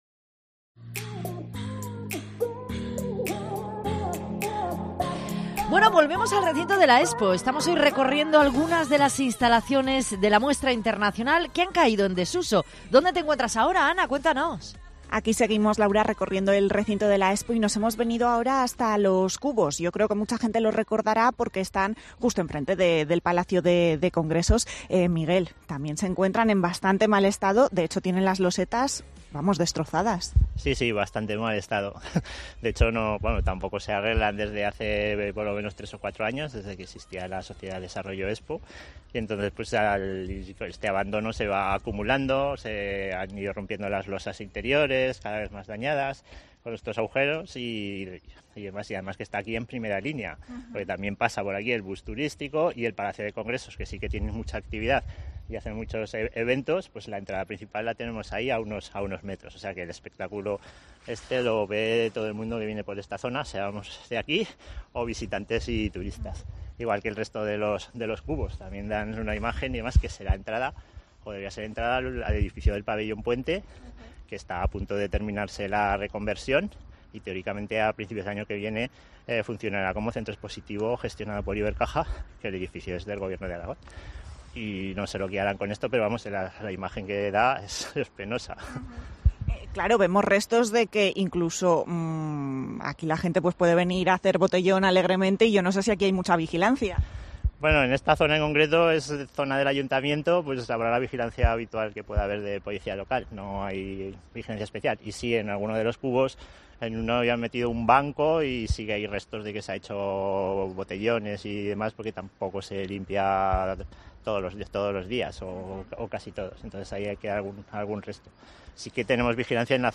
Recorremos el recinto Expo: ¿Cómo se encuentra 14 años después?